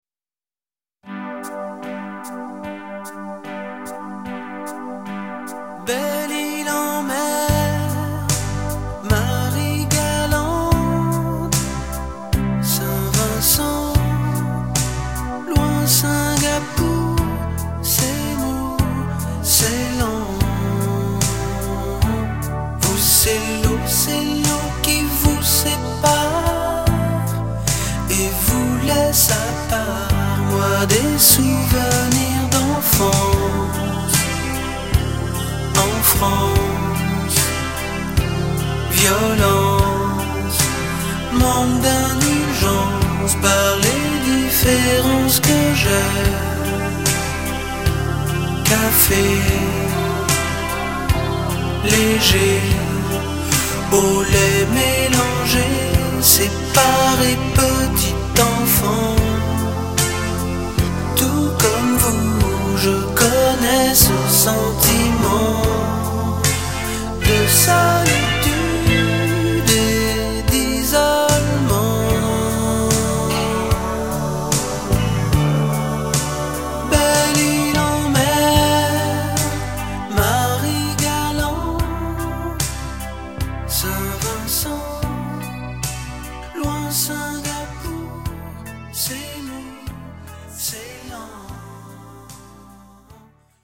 tonalité SOL majeur